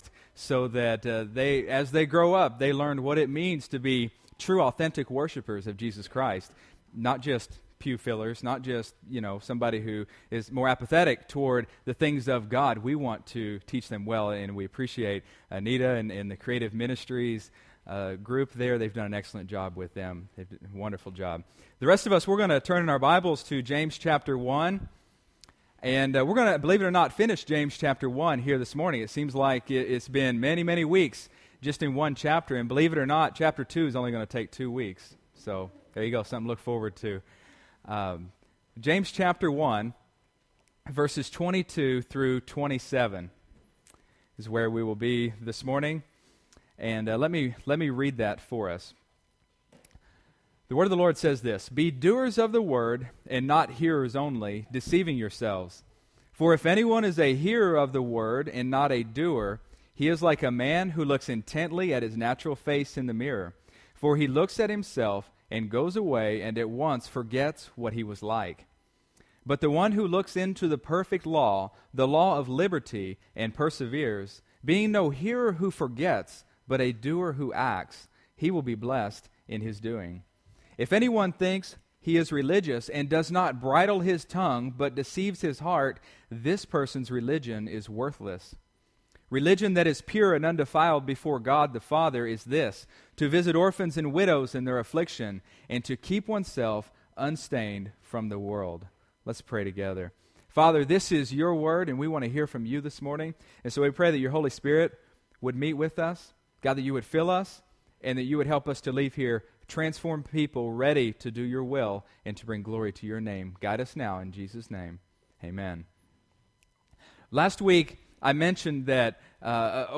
October 19, 2014 (Sunday Morning Service)